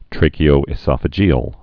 (trākē-ōĭ-sŏfə-jēəl)